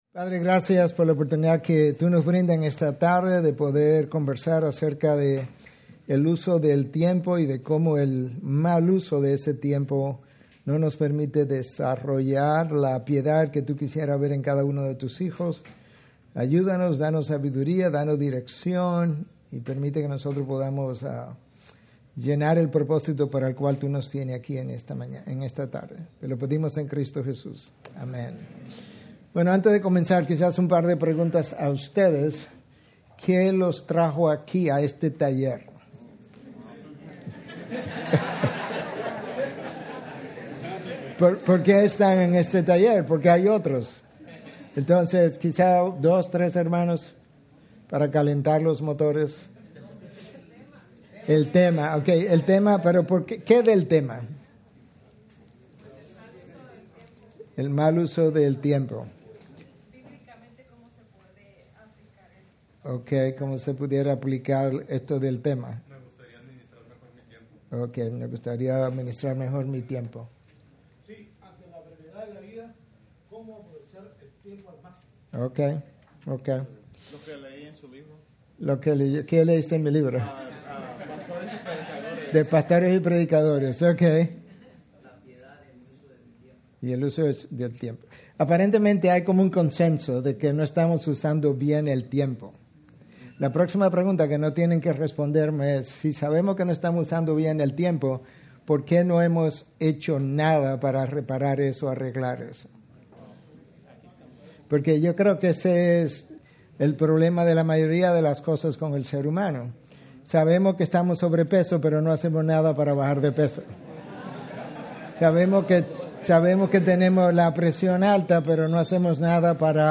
Taller: Usando el tiempo sabiaente | Conferencia Expositores | Grace Community Church